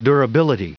Prononciation du mot durability en anglais (fichier audio)
Prononciation du mot : durability